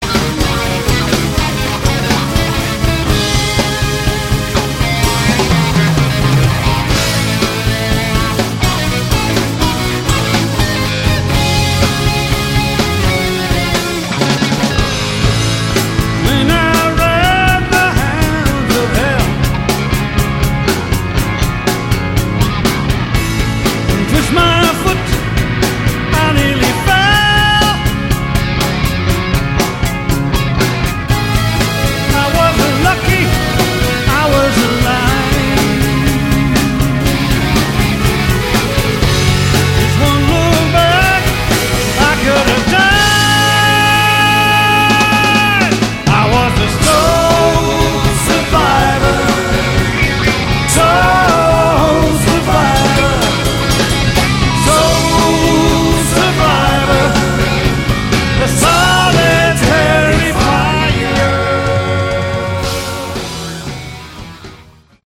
Category: Prog Rock
bass, vocals
drums
guitars
keyboards